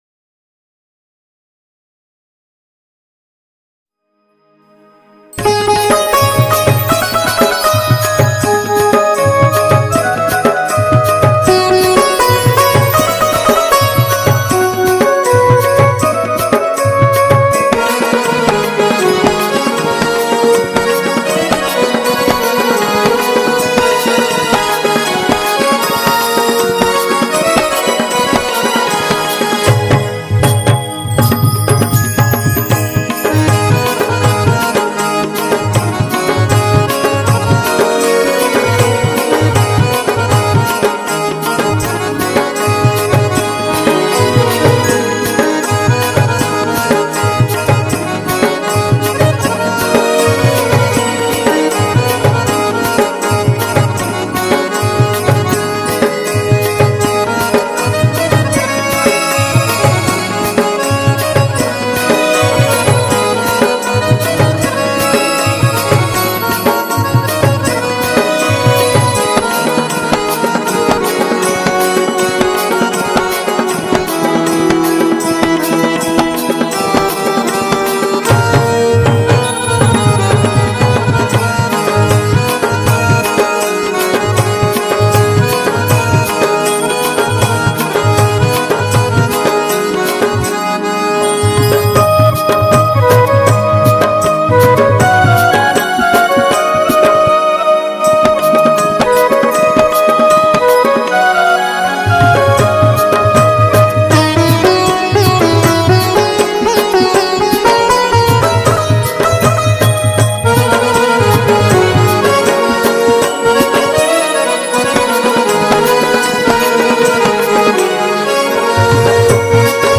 cover Credit Keyboard
Instrumental Music And Rhythm Track